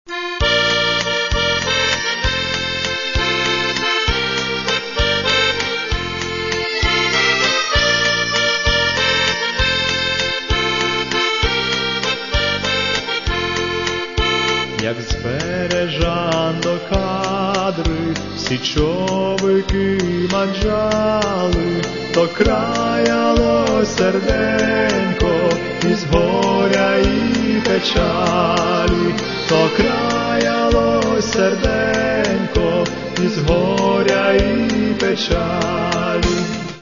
Каталог -> Народна -> Традиційне виконання